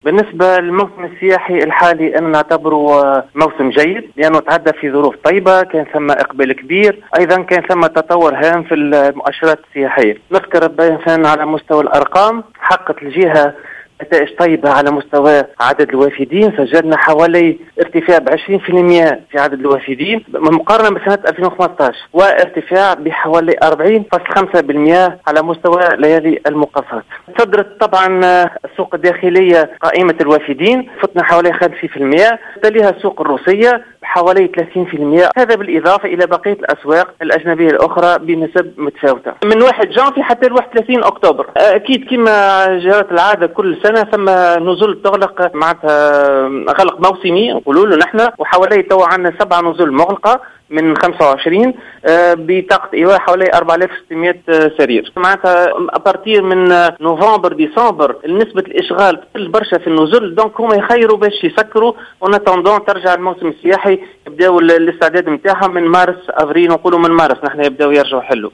أكد المندوب الجهوي للسياحة بالمهدية محمد بوجدارية في تصريح ل"الجوهرة أف أم" أن 7 وحدات فندقية أغلقت أبوابها في الجهة على أن تستأنف نشاطها قبل الموسم السياحي القادم.